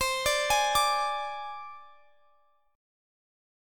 Listen to Csus2#5 strummed